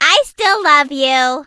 colette_die_vo_02.ogg